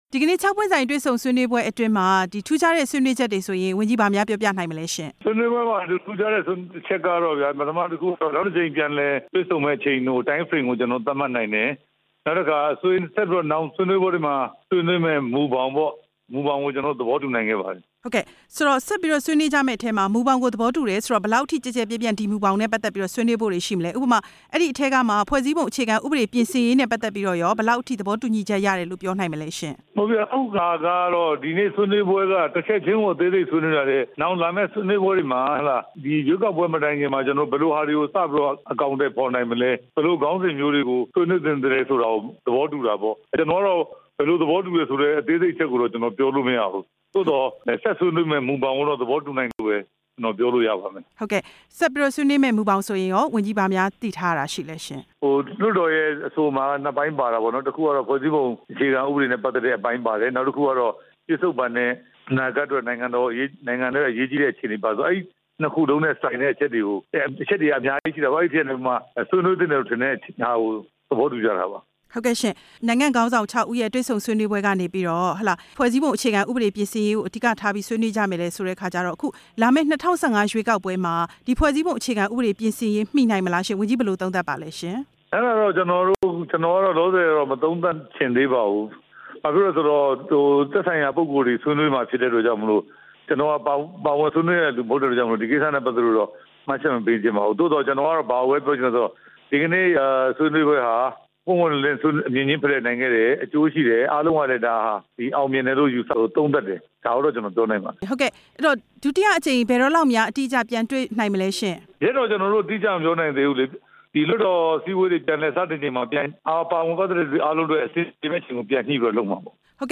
ဦးရဲထွဋ်နဲ့ မေးမြန်းချက်